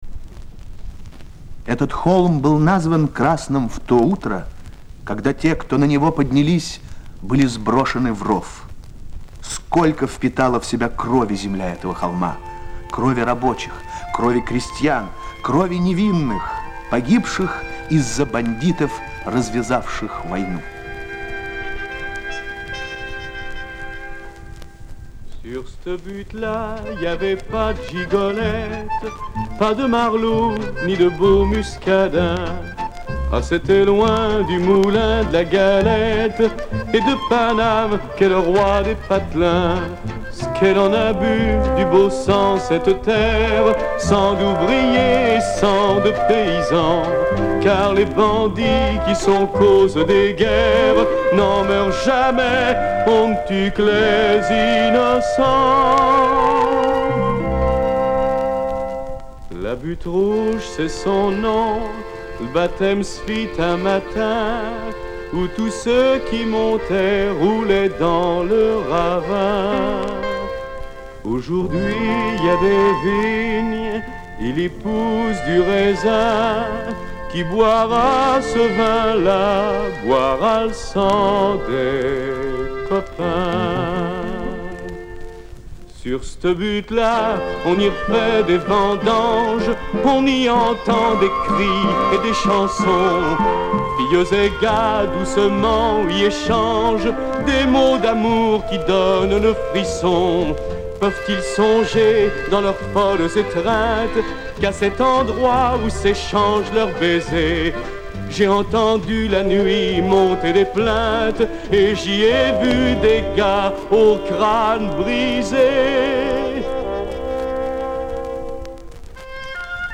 Их исполняет молодой певец
в сопровождении оркестра
звучат страстно, гневно
Песни шансонье